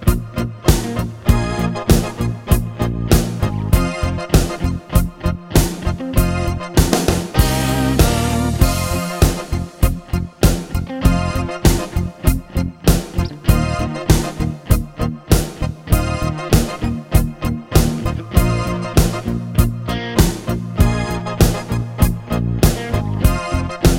No Main Guitar Rock 5:22 Buy £1.50